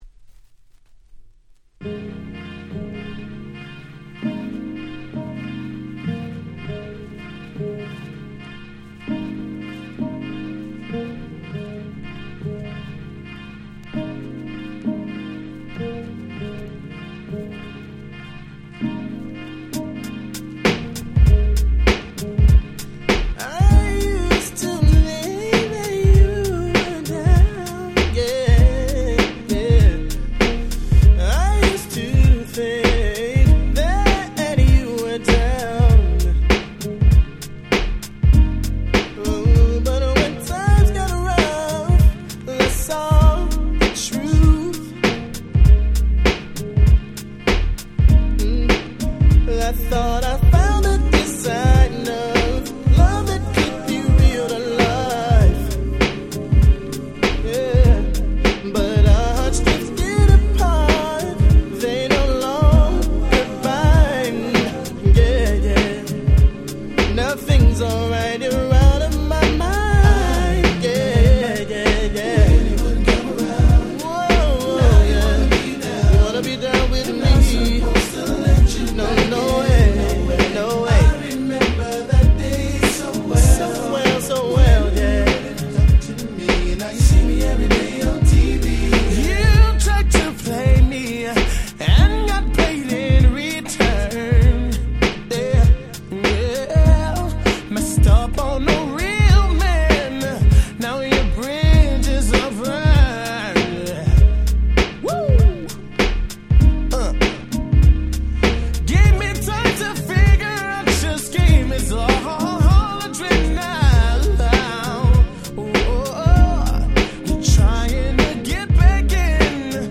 95' Very Nice R&B / Hip Hop Soul !!
スーパーヒットの多い彼らにとっては控えめなヒットなのかもですが切ない疾走感が堪らない最高のR&Bです！